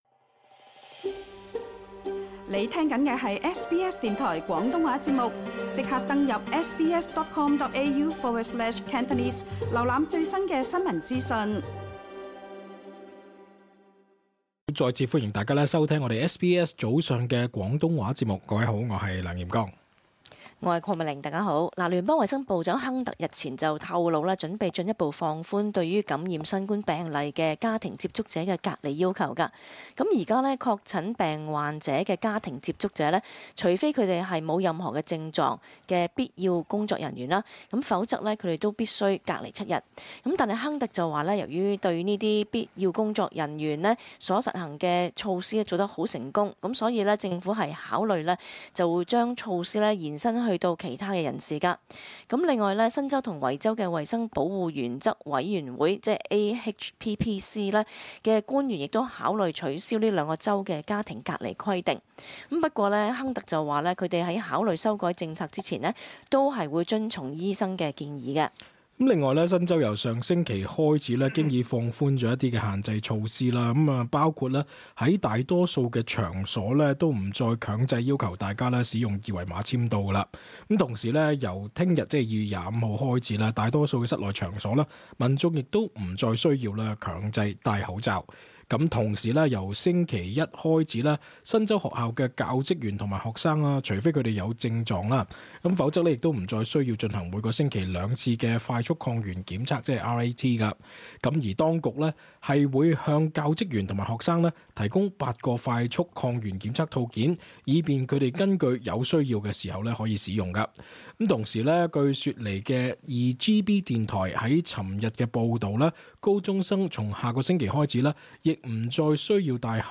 cantonese_-_talkback_-feb_24_-_final_upload.mp3